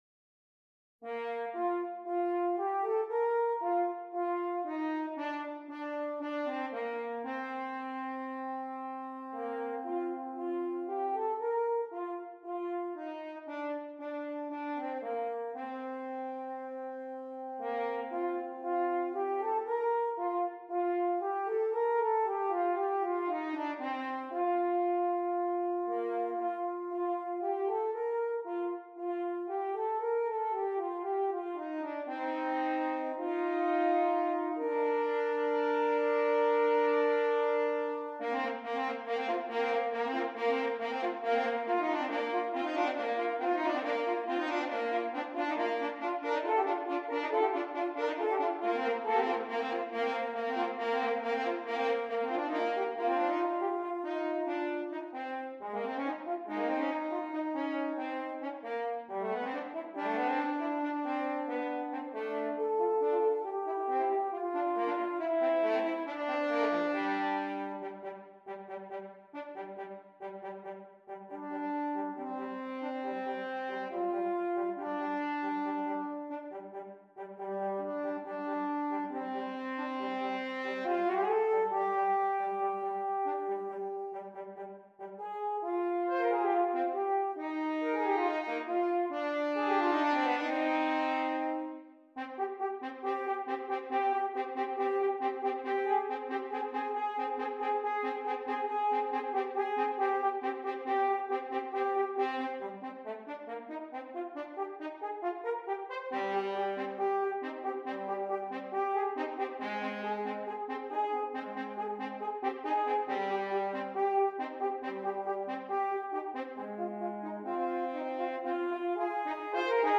2 F Horns